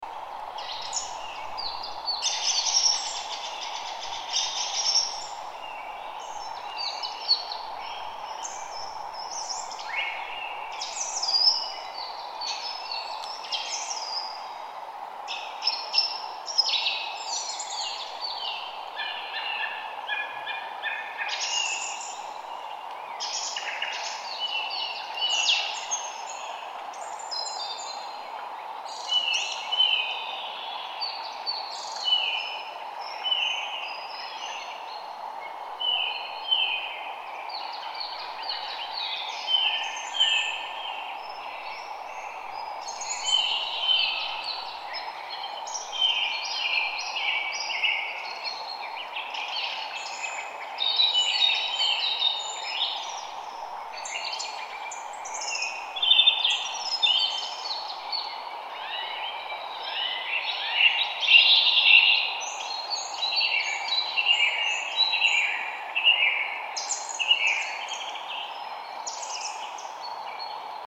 Animal Sounds / Bird Sounds / Sound Effects 8 Feb, 2026 Relaxing Forest Sounds With Birds Read more & Download...
Relaxing-forest-sounds-with-birds.mp3